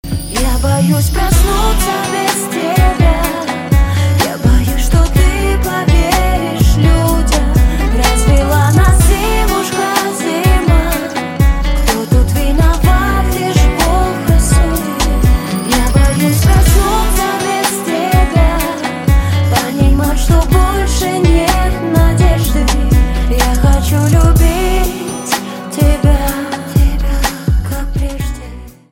• Качество: 320, Stereo
поп
женский вокал
грустные